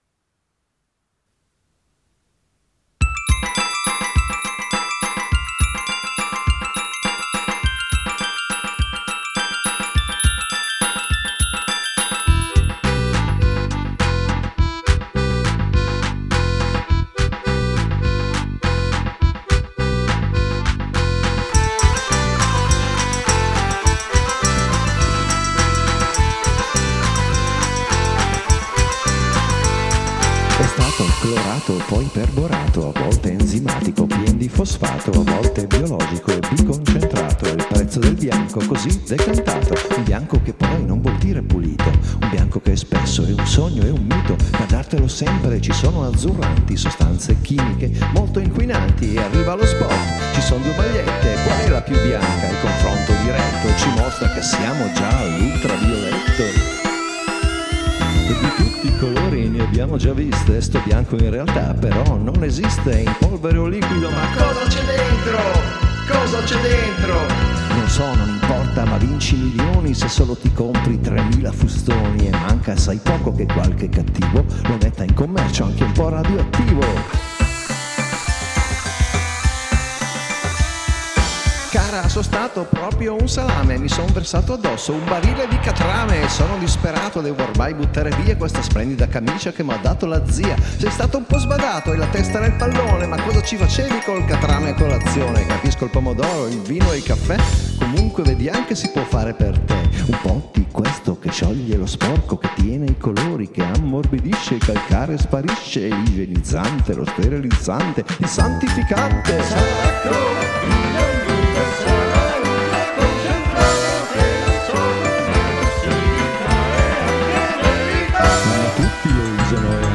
Musica, parole, chitarra, basso,
Queste considerazioni sono il contenuto di “Ora Esatta”, una serie di scene quasi teatrali, a volte più recitate sopra la colonna sonora che cantate in modo classico.